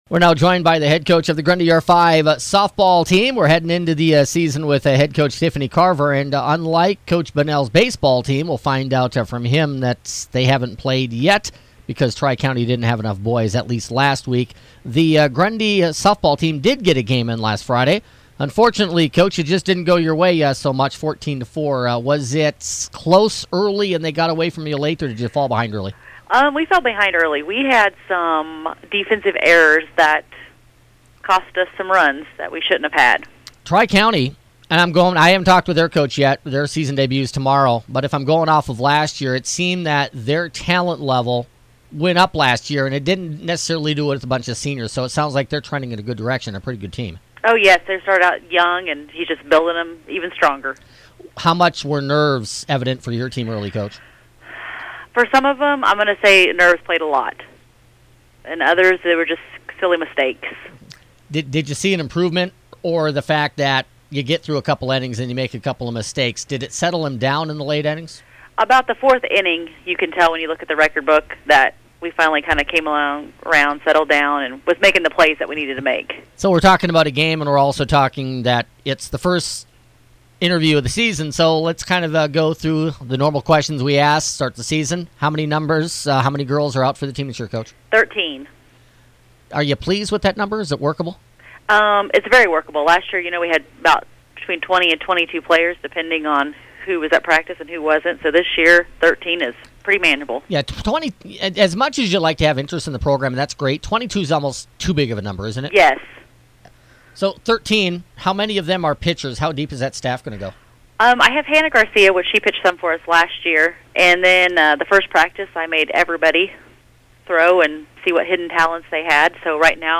Grundy R-5 coaches shows for Monday, August 27th